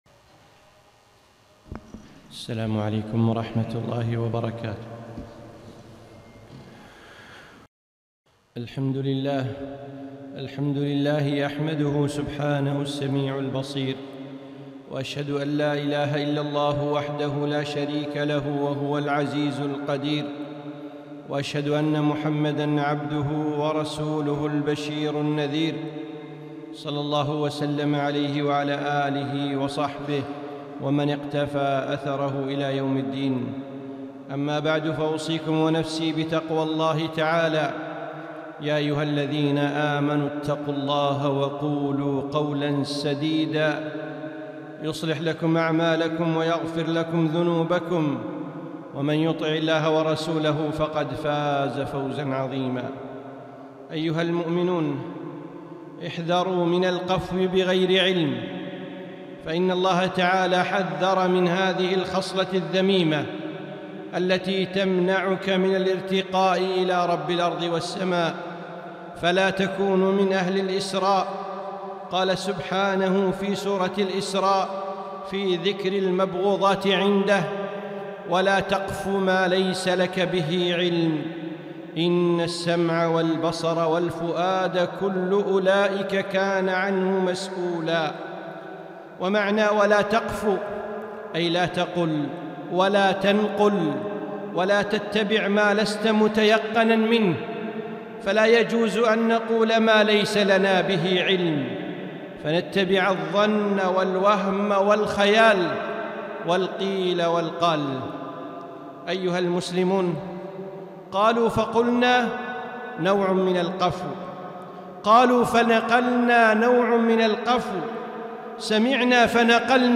خطبة - إياك والقفو - دروس الكويت